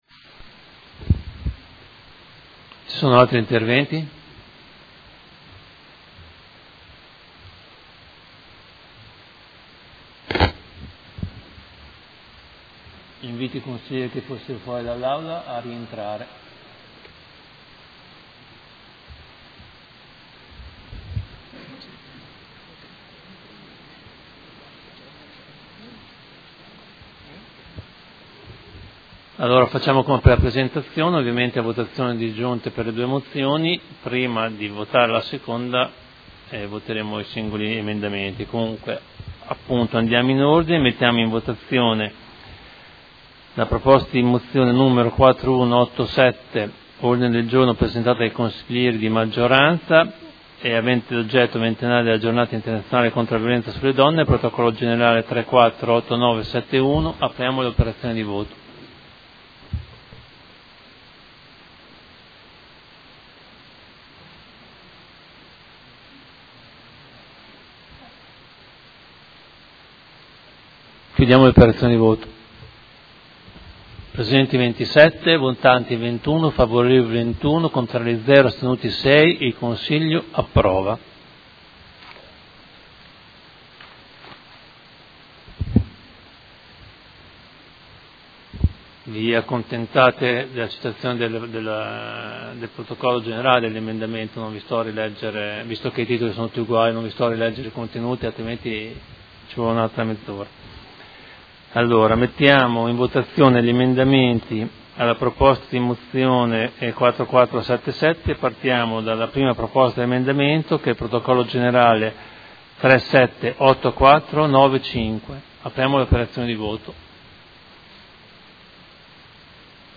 Presidente — Sito Audio Consiglio Comunale
SEDUTA DEL 16/01/2020.
Seduta del 16 gennaio 2020